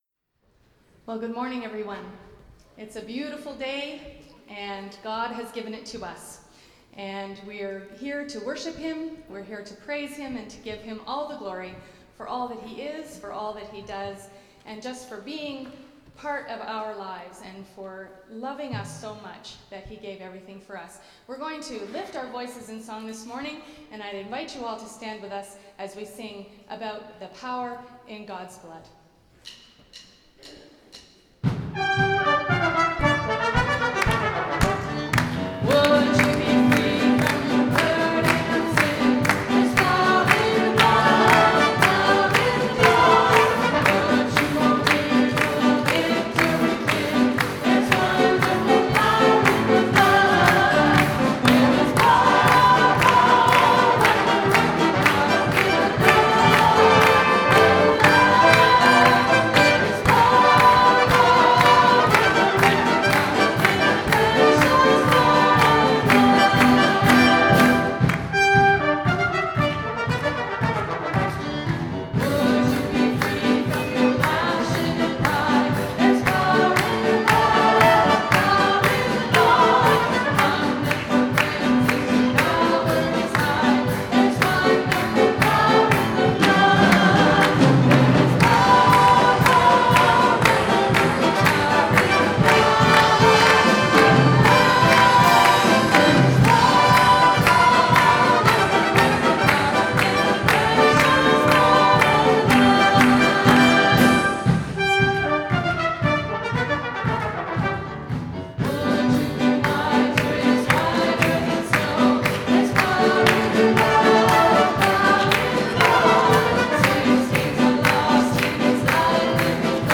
Worship Team Set.ogg